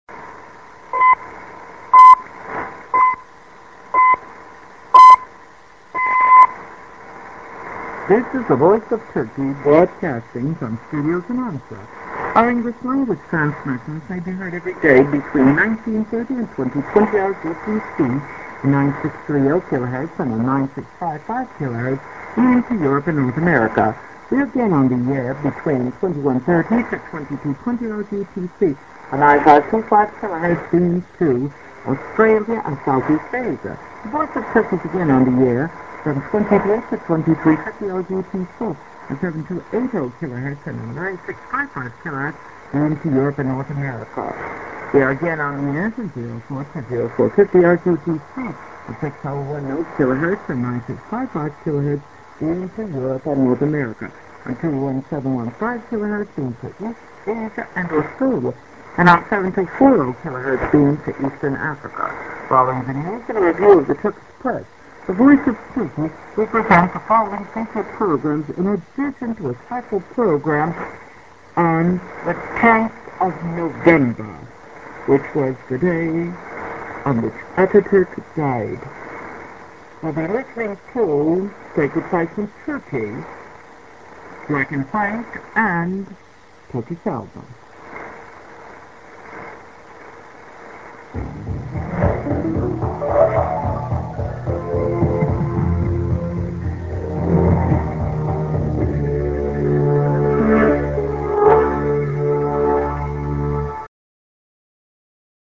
b:　TS->ID+SKJ(women)->Music